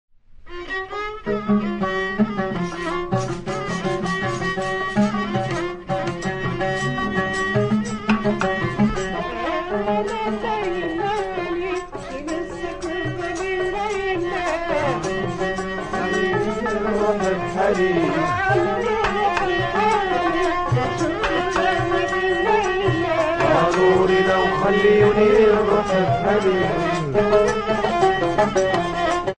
REBEB; RABAB | Soinuenea Herri Musikaren Txokoa
Tripazko bi soka ditu.
Arkua du sokak igurtzitzeko.
Instruments de musique: REBEB; RABAB Classification: Cordes -> Frottées Emplacement: Erakusketa; kordofonoak Explication de l'acquisition: Erosia; 1983ko urrian Fez-eko denda batean erosia.